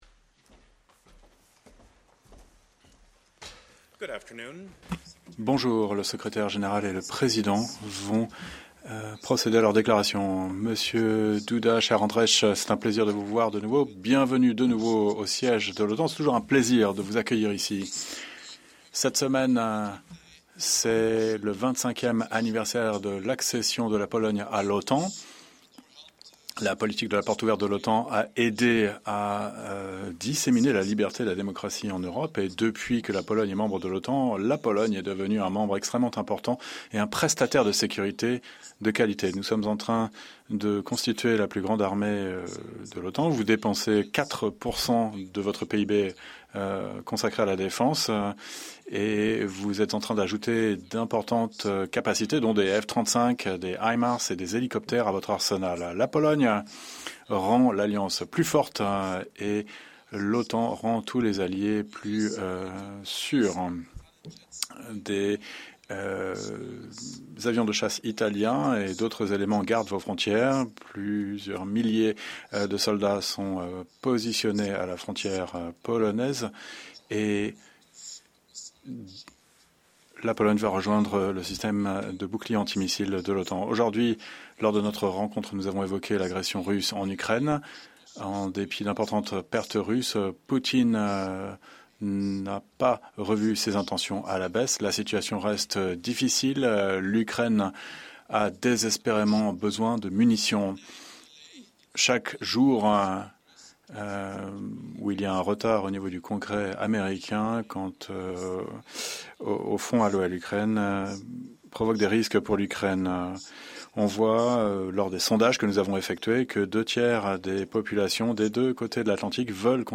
Joint press conference
by NATO Secretary General Jens Stoltenberg with the President of Poland Andrzej Duda